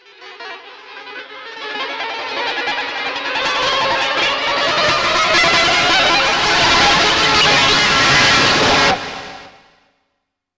Index of /90_sSampleCDs/Spectrasonics - Bizarre Guitar/Partition H/07 SCRAPE SW